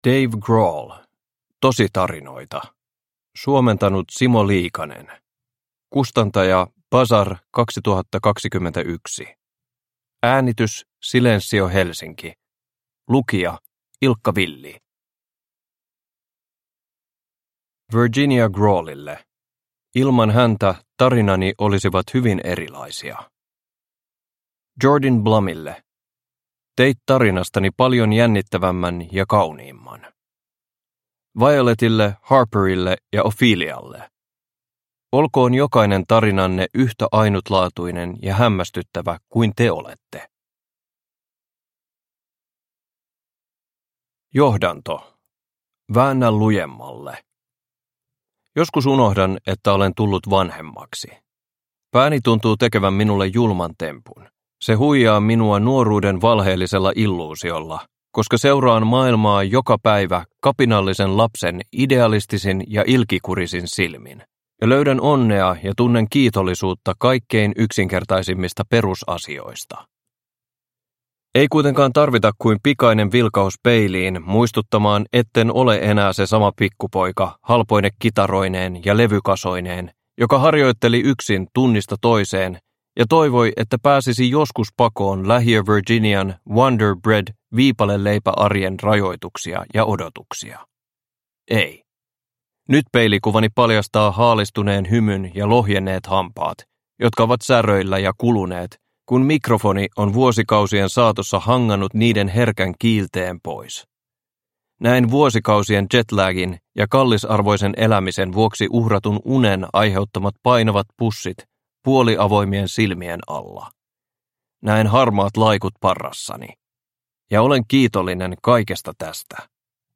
Tositarinoita – Ljudbok
Uppläsare: Ilkka Villi